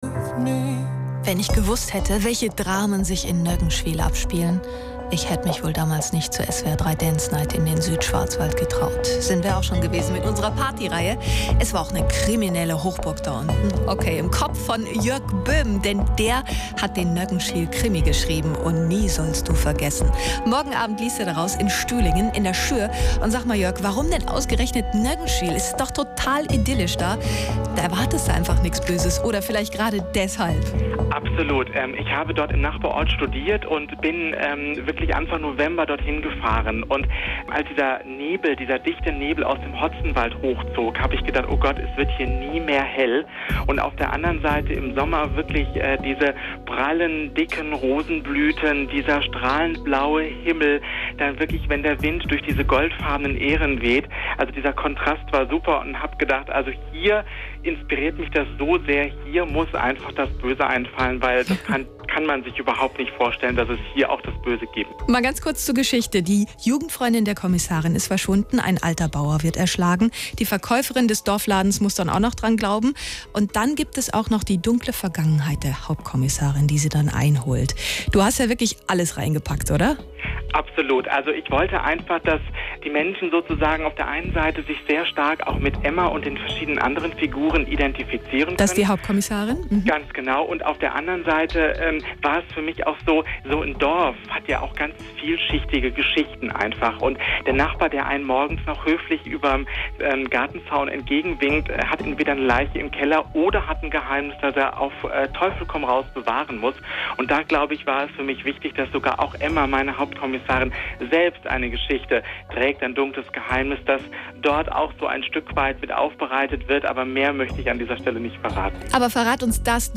SWR 3 -Interview   zum ersten Emma-Hansen-Krimi.